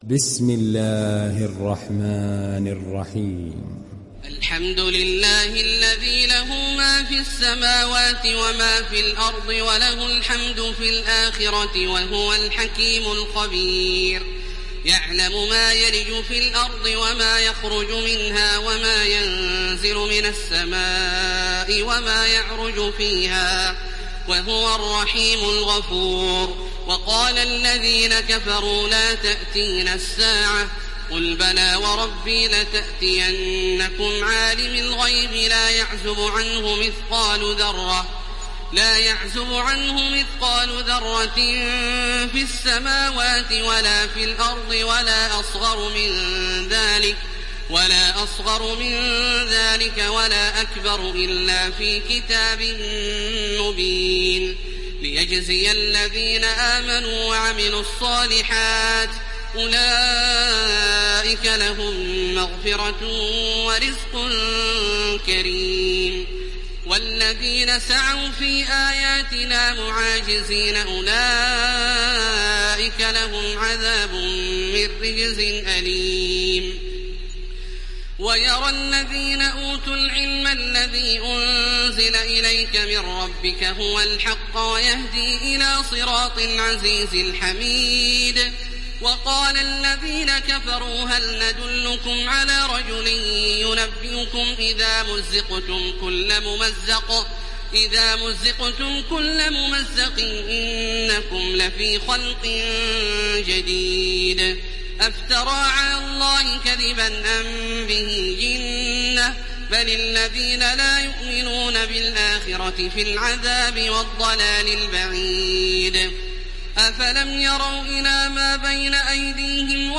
تحميل سورة سبأ mp3 بصوت تراويح الحرم المكي 1430 برواية حفص عن عاصم, تحميل استماع القرآن الكريم على الجوال mp3 كاملا بروابط مباشرة وسريعة
تحميل سورة سبأ تراويح الحرم المكي 1430